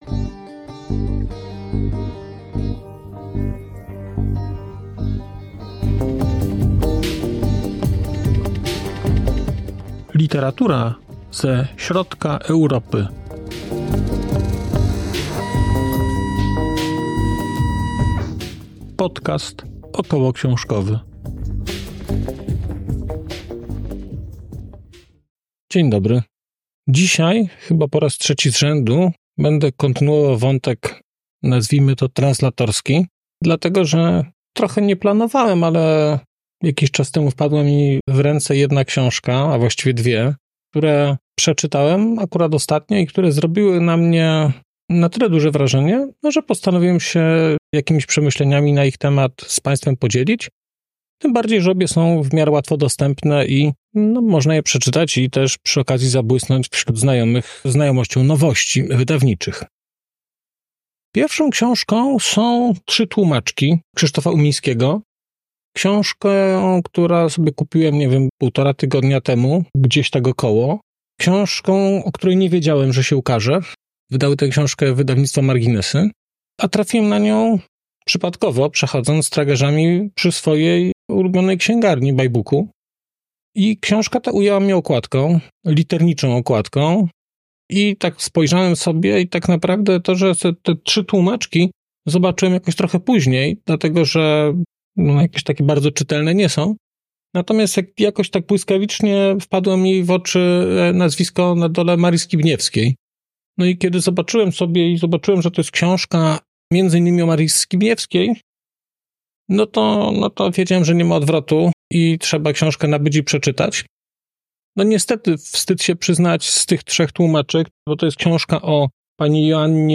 🔧 odcinek zremasterowany: 25.01.2025